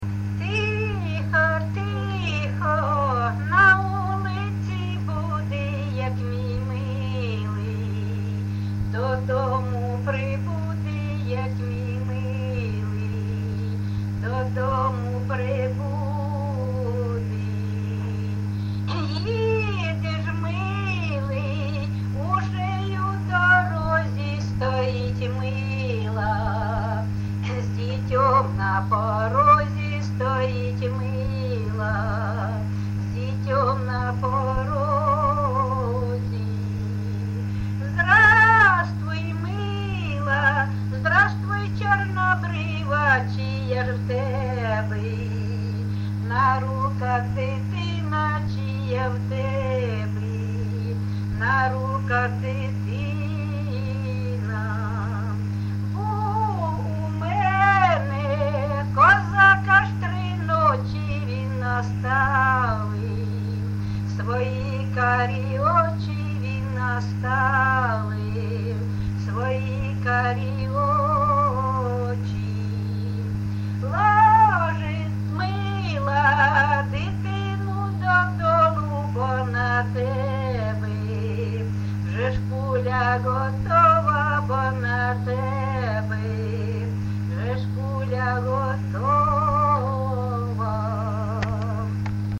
ЖанрПісні з особистого та родинного життя, Балади
Місце записус. Бузова Пасківка, Полтавський район, Полтавська обл., Україна, Полтавщина